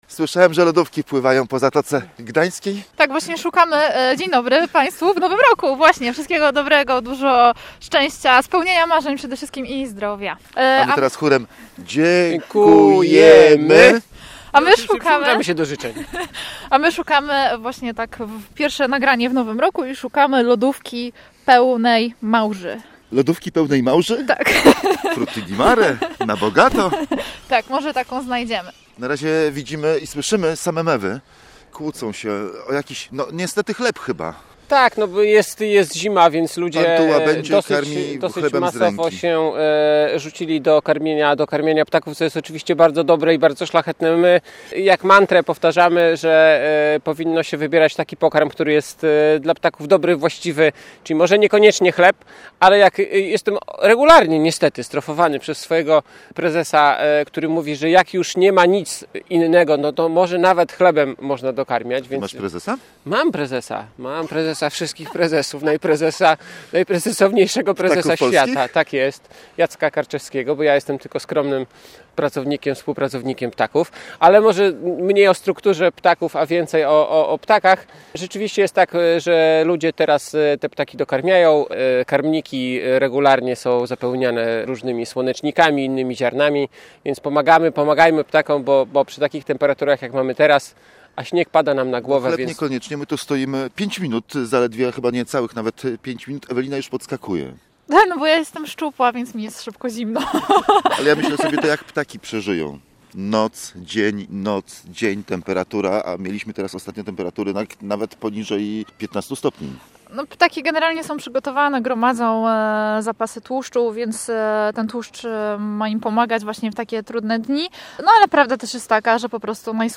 Jesteśmy na pTAK i pod takim hasłem emitujemy na naszej antenie ptasie audycje.